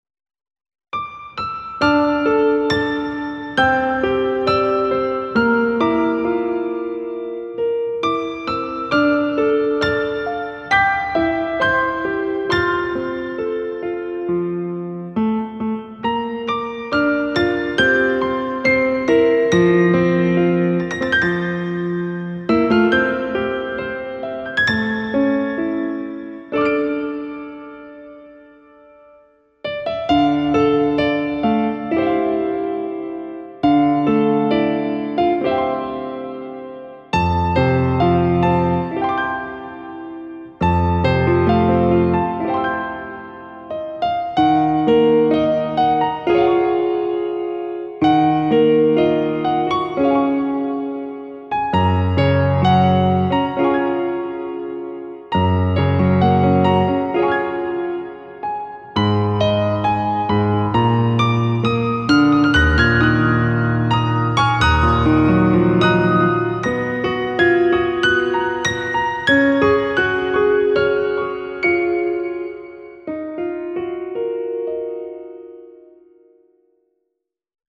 メロディー主体のフリーBGM音源です。
通常版よりクリアーでハイファイなサウンドです。